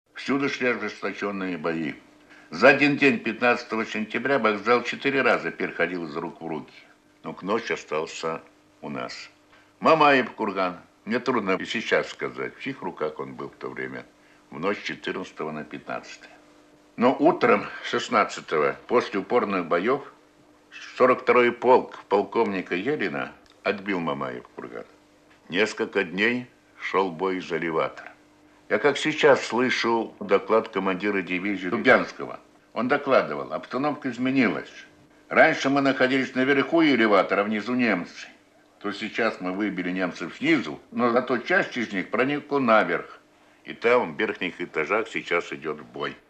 Маршал Советского Союза Василий Чуйков вспоминает о боях за Сталинград (Архивная запись).